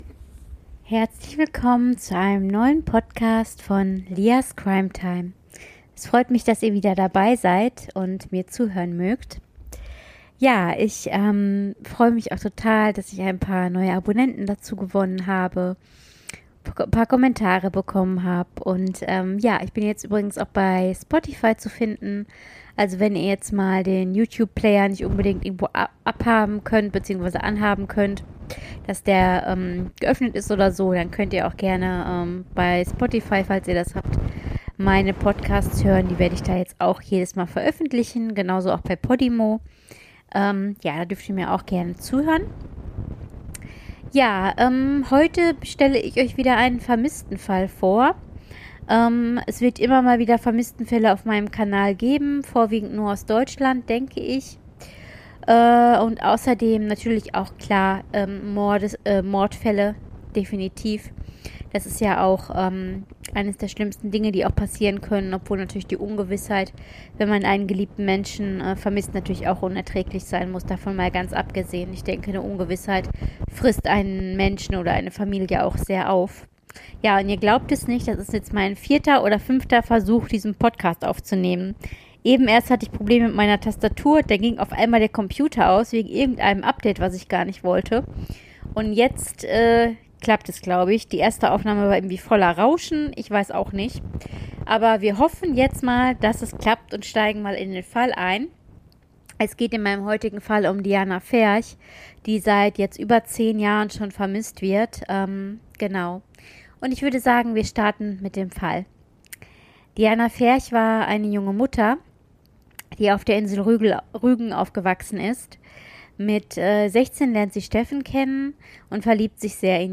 Dieser Podcast rauscht leider teilweise etwas, dafür sage ich SORRY!
TRUE CRIME PODCAST